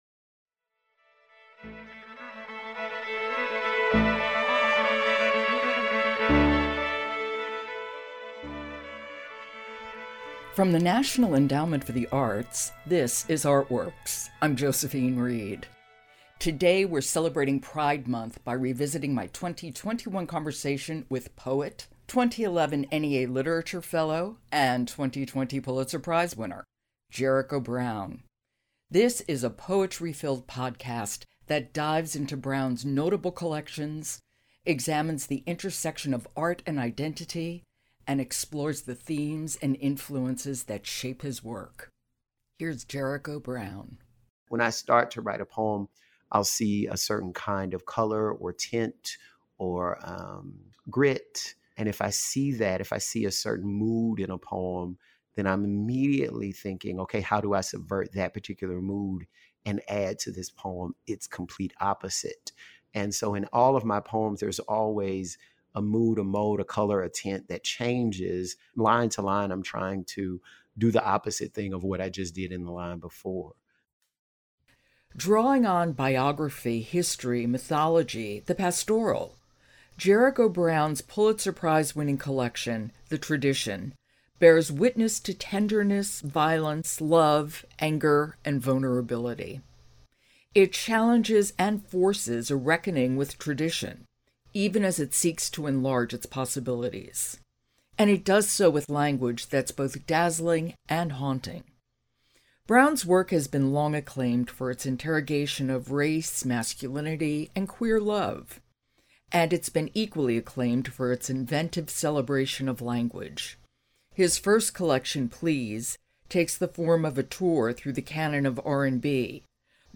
Jericho Brown reads and discusses his work, his creative process, and Black queer poetry’s capacity to expand concepts of love.